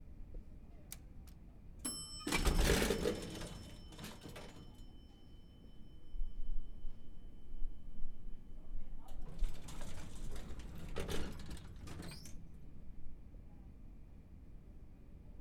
elevator door and ding